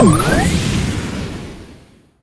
special_jetpack_start.ogg